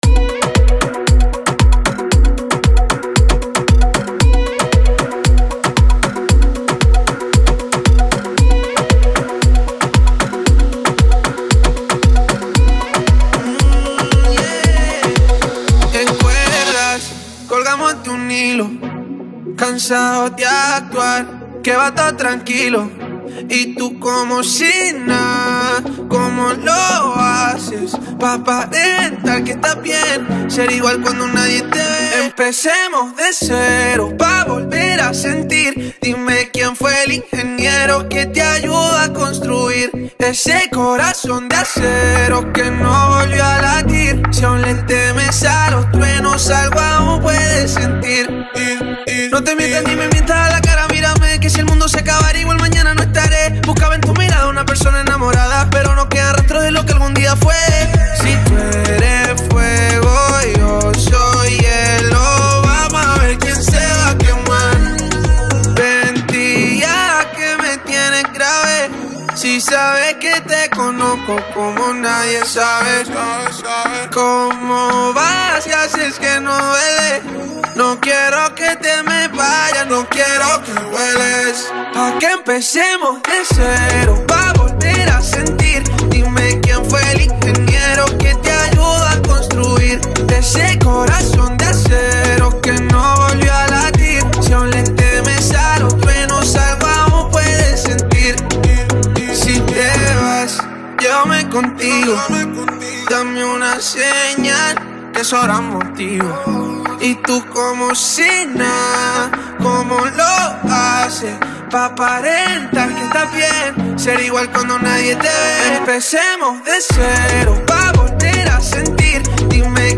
BPM: 115